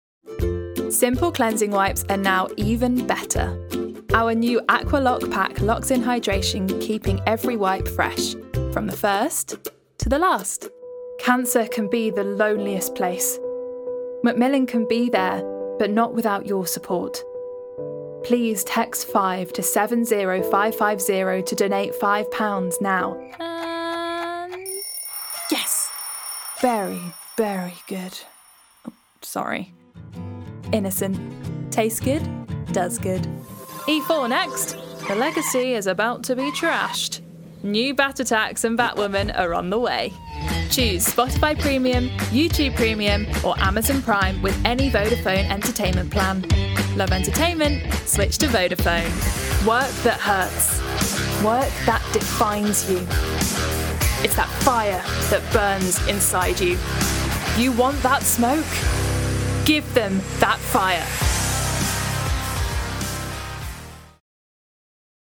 20s-30s. Female. RP/Midlands. Studio.